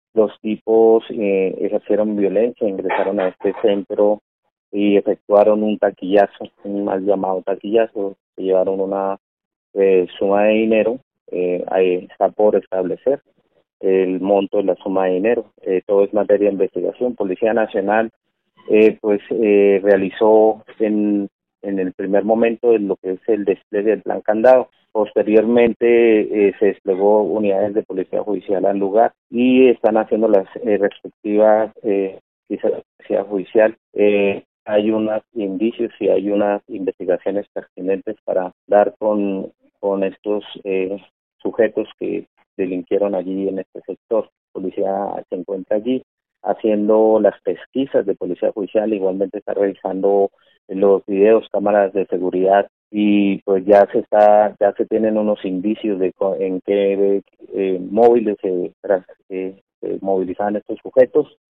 Fernando García, Secretario de Seguridad y Convivencia de Piedecuesta